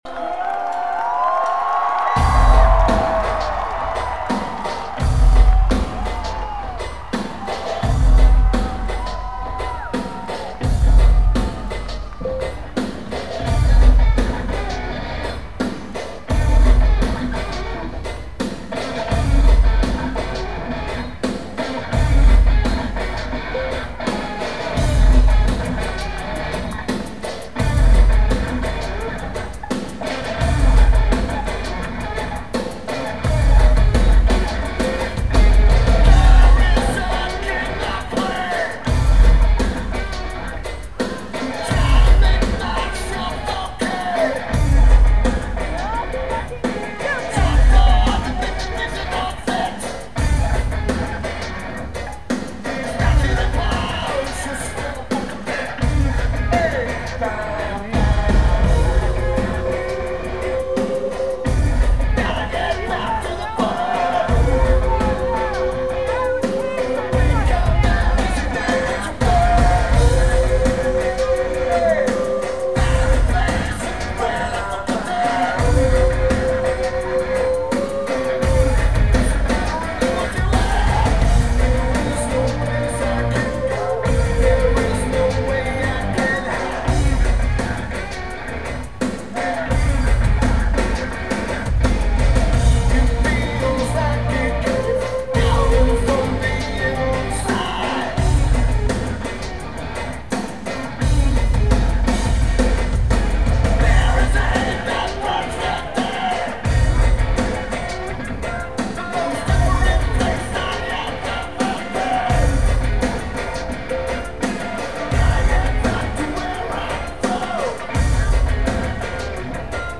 Clark County Amphitheater
Lineage: Audio - AUD (CSB's + Sharp MD- MT15)
Notes: Recorded from GA, Center stage, about 14 rows back.